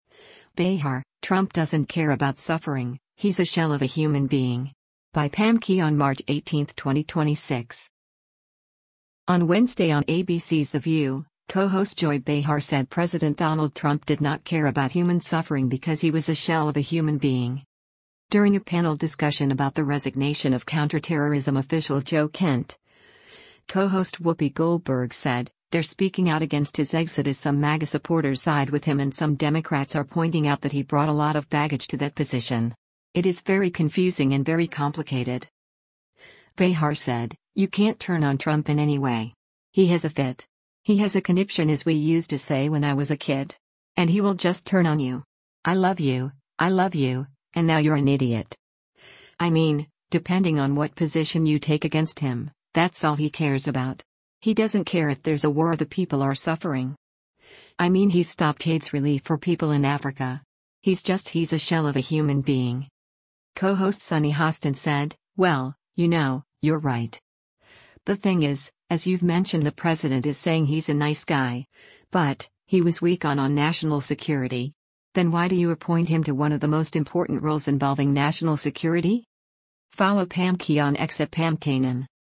On Wednesday on ABC’s “The View,” co-host Joy Behar said President Donald Trump did not care about human suffering because he was a “shell of a human being.”
During a panel discussion about the resignation of counterterrorism official Joe Kent, co-host Whoopi Goldberg said, “They’re speaking out against his exit as some MAGA supporters side with him and some Democrats are pointing out that he brought a lot of baggage to that position.